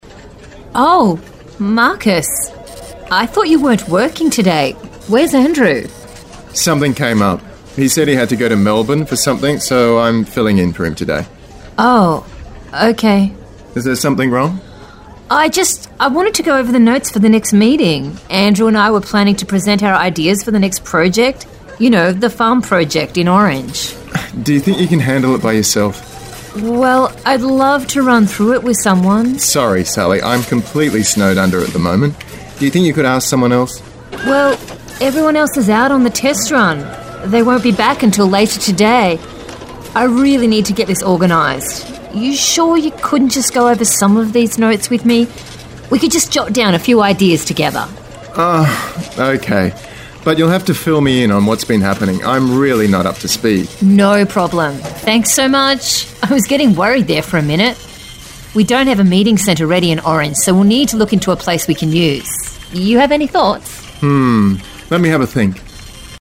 Below is a short dialogue using these phrasal verbs so you can see them more in context.
OFFICE-DIALOGUE.mp3